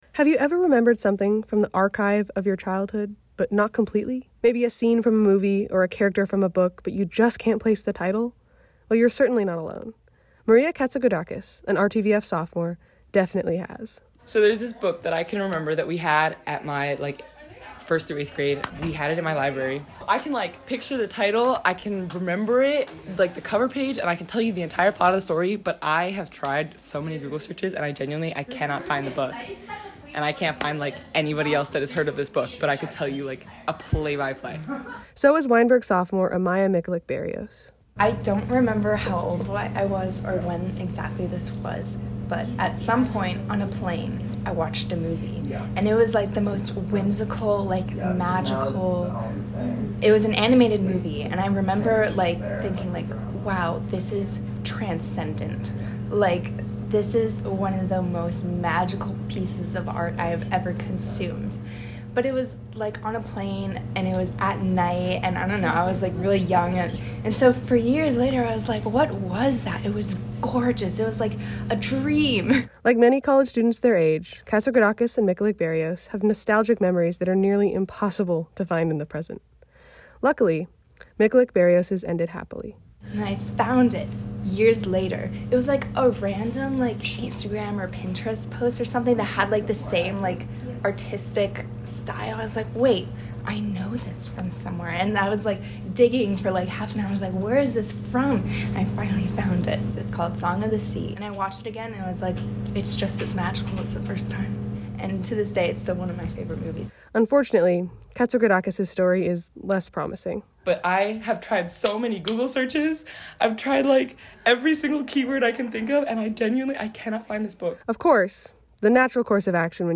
Music: How Can I Forget You? By Faron Young